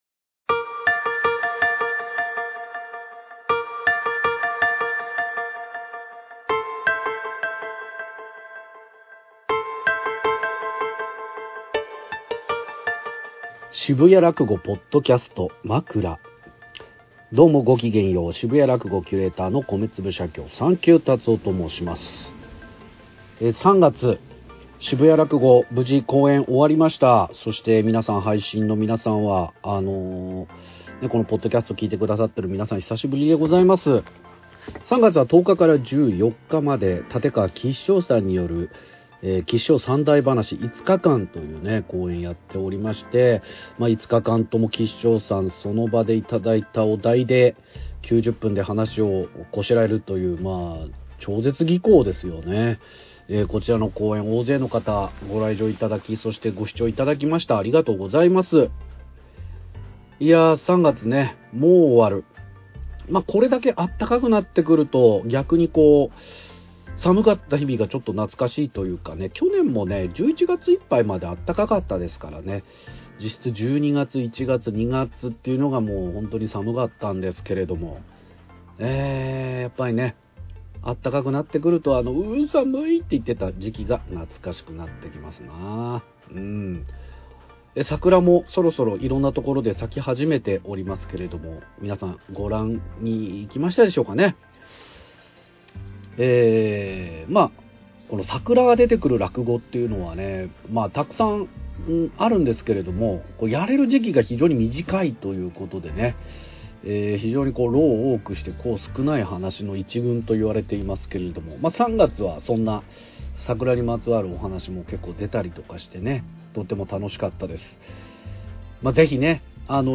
渋谷らくごのポッドキャスト渋谷のユーロライブで行われている「渋谷らくご」。そこで収録された「まくら」を配信中です。